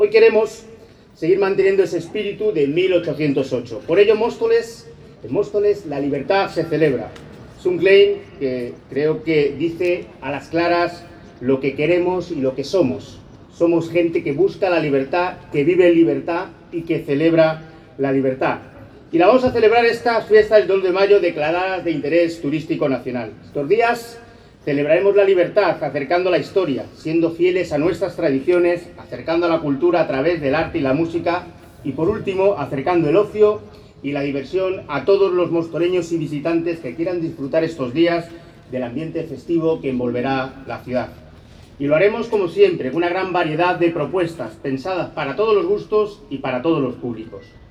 Audio - Manuel Bautista (Alcalde de Móstoles) - Fiestas 2 de Mayo 2026